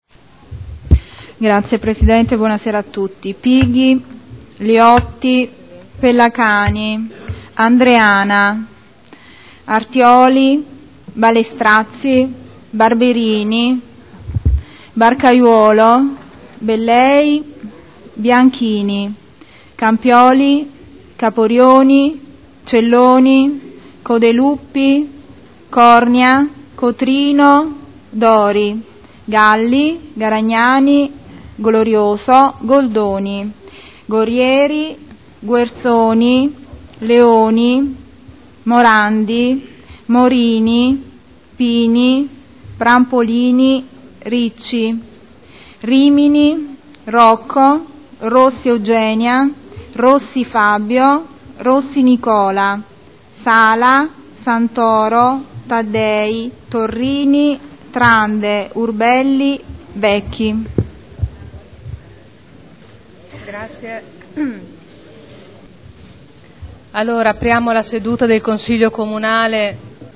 Apertura del Consiglio Comunale. Appello
Segretario Generale